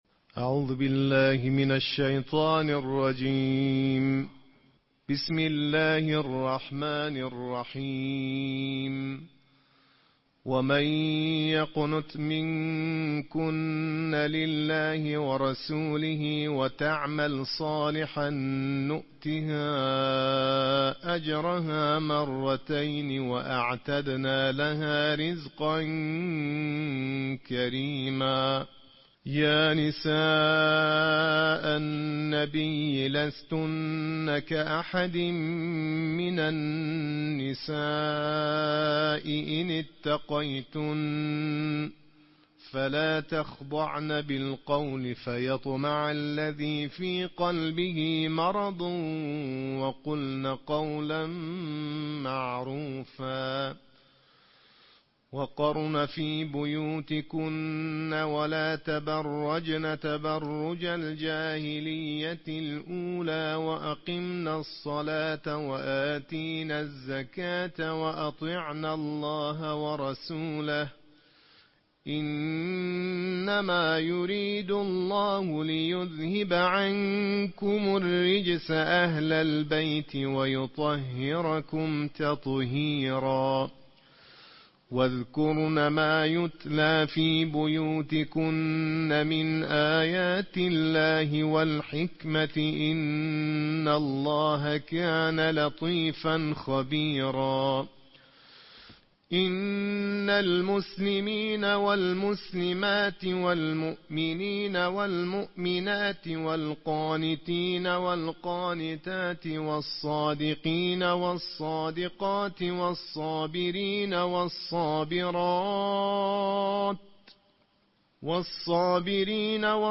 अंतर्राष्ट्रीय क़ारियों की आवाज़ के साथ कुरान के बाईसवें भाग का पाठ + ऑडियो